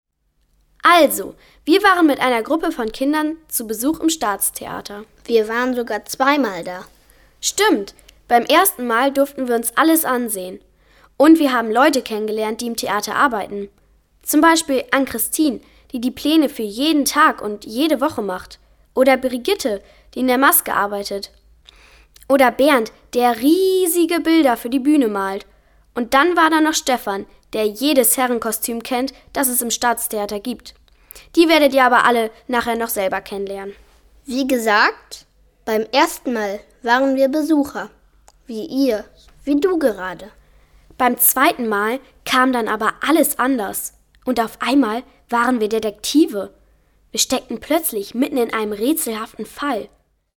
Ein kriminalistischer Audiorundgang hinter den Kulissen des Oldenburgischen Staatstheaters.
Der Lauschangriff Staatstheater ist ein begehbares Hörspiel, das die Hörerin und den Hörer mitten durch das Staatstheater führt. Es wurde entwickelt und gesprochen von Kindern im Alter zwischen 8 und 14 Jahren.
Bei den anschließenden Tonaufnahmen wurden Teile, ähnlich wie bei den Hörgängen Oldenburg, binaural aufgenommen, so dass die Hörerin und der Hörer während des Hörspiels immer mitten im Geschehen steht und so manche Figur plötzlich fast greifbar neben einem erscheint.